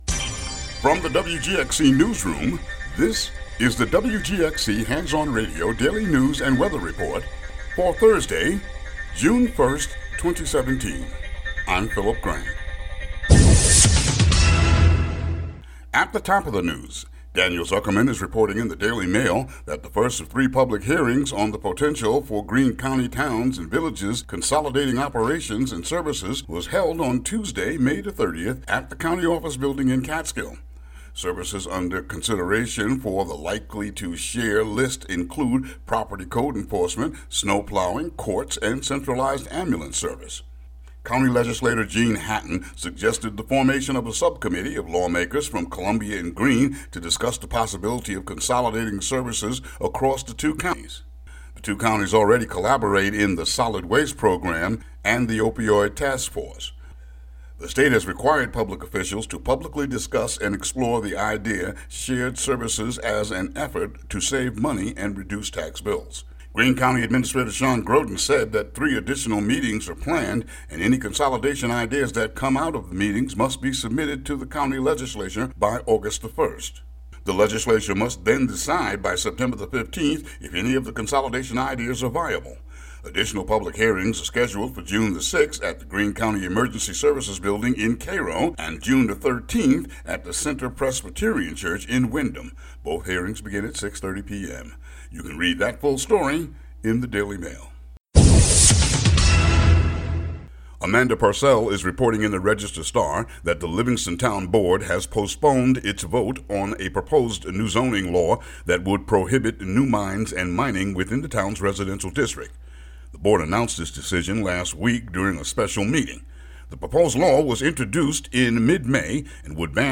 WGXC daily headlines for Jun. 1, 2017.